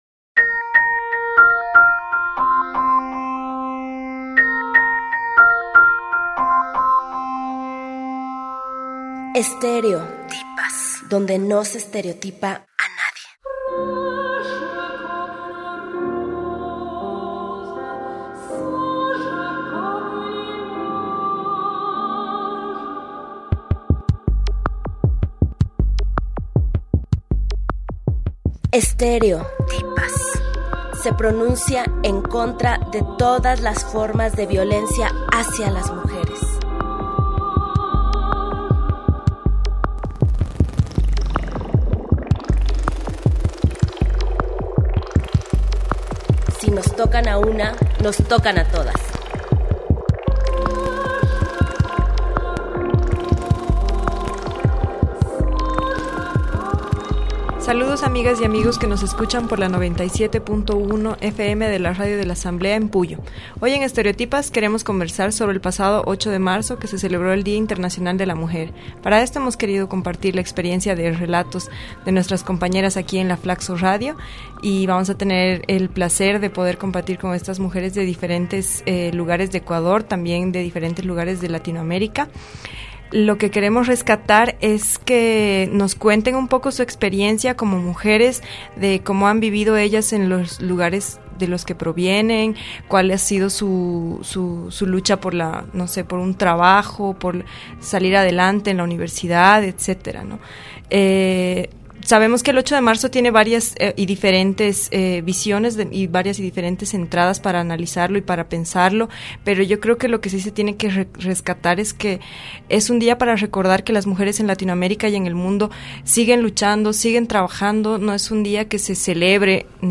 Estéreo-Tipas por el día internacional de mujer, 8 de Marzo, realizó un programa que rescata la historia de vida y experiencias de dos compañeras de la FLACSO radio.